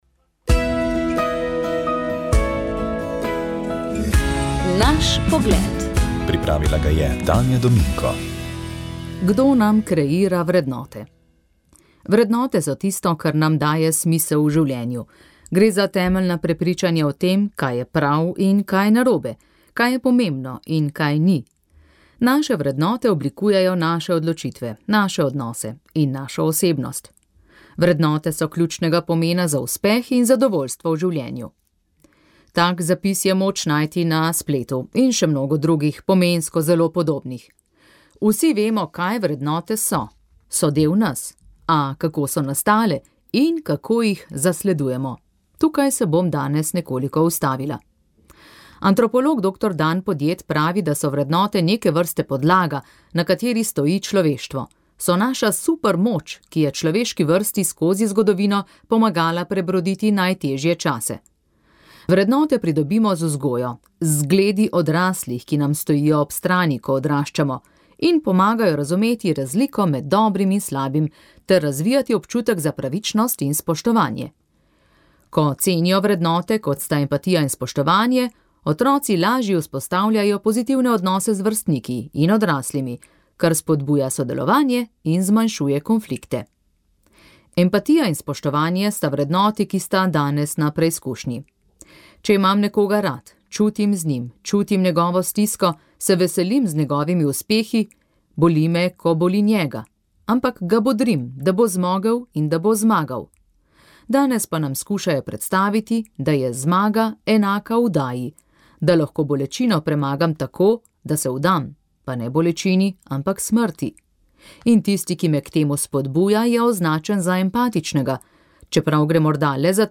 Pogovor s predsednikom Nove Slovenije Matejem Toninom